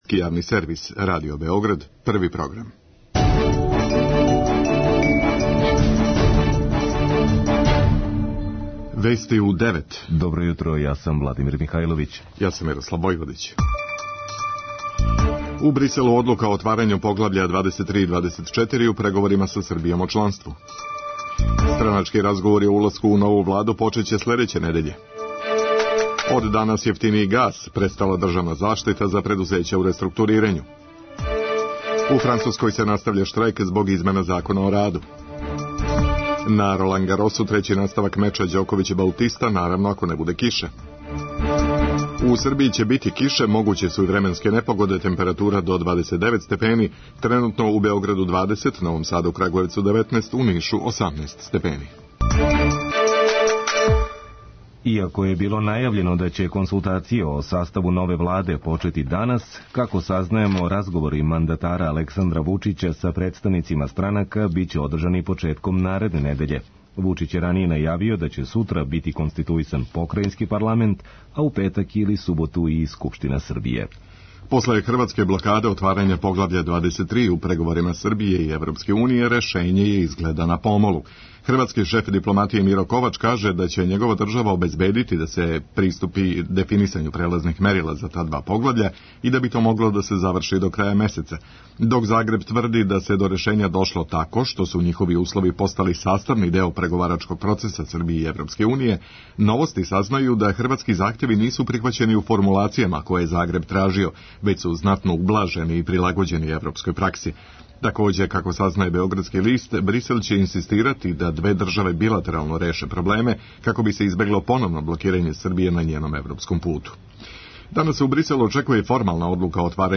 преузми : 4.03 MB Вести у 9 Autor: разни аутори Преглед најважнијиx информација из земље из света.